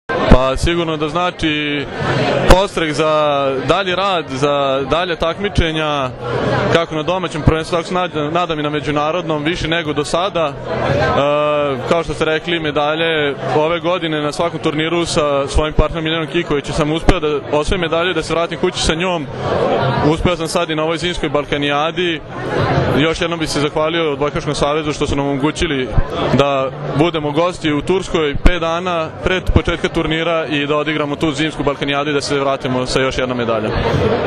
Odbojkaški savez Srbije je danas u beogradskom hotelu “Metropol Palas” organizovao Novogdišnji koktel “Naša strana mreže”, na kojem su dodeljeni Trofeji “Odbojka spaja”, “Budućnost pripada njima”, Trofeji za najbolju odbojkašicu i odbojkaša i najbolju odbojkašicu i odbojkaša na pesku, kao i prvi put “specijalna plaketa OSS”.
IZJAVA